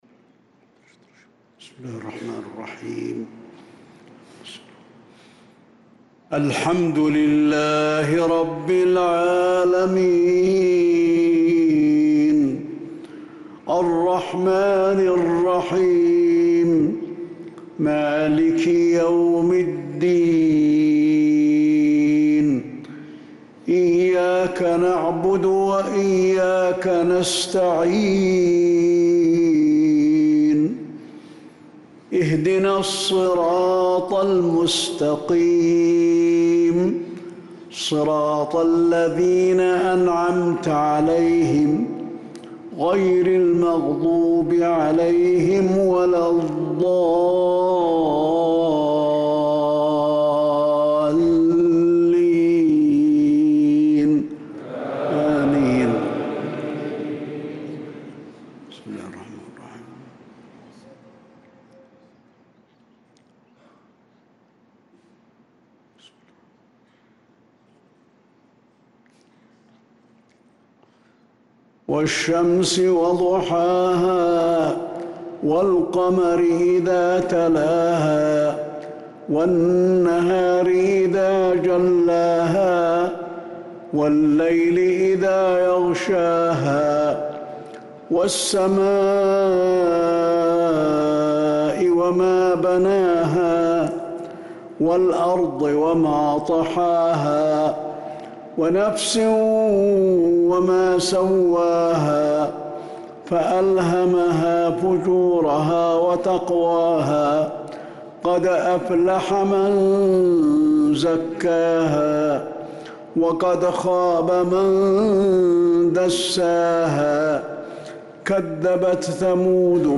صلاة العشاء للقارئ علي الحذيفي 9 ذو القعدة 1445 هـ
تِلَاوَات الْحَرَمَيْن .